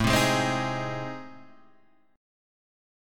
AmM7b5 chord